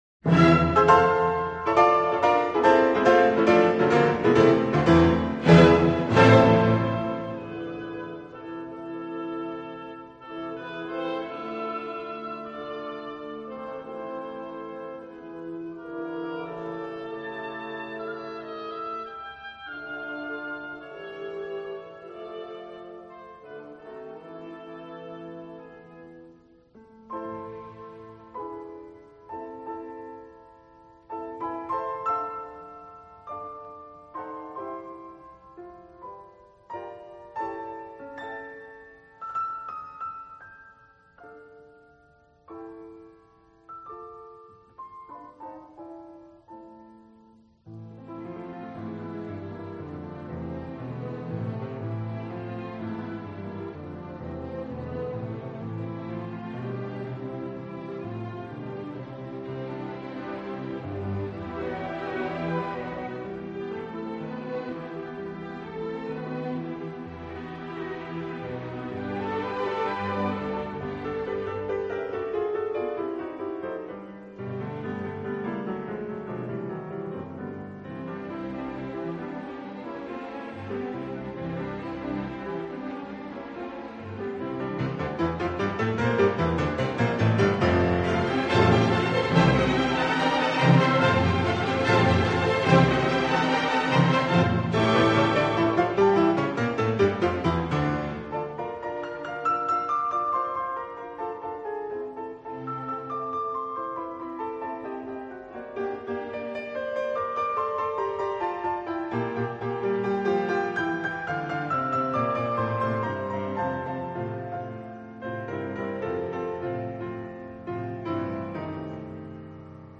Schumann, Robert - Piano Concerto, Op.54 Free Sheet music for Piano and Ensemble
Style: Classical
Jerusalem Symphony Orchestra